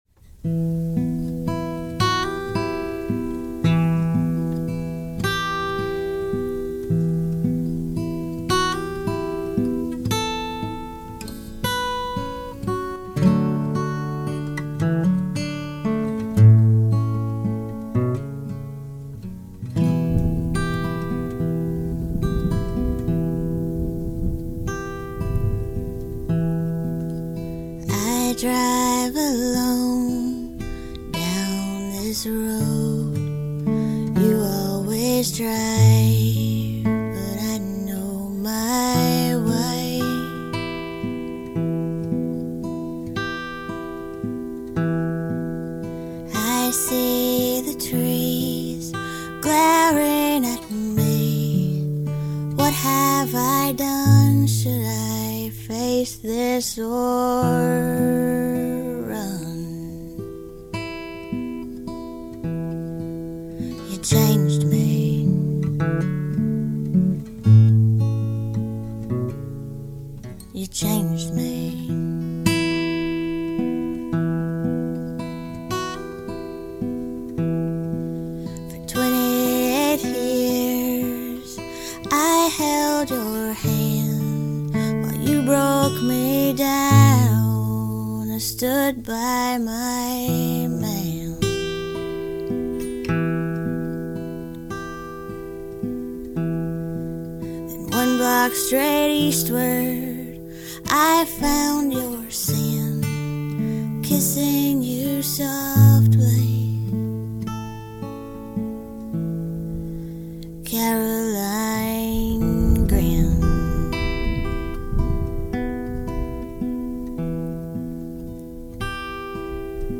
Down-Tempo
Folk
lo-fi, Singer Songwriter
pixie voice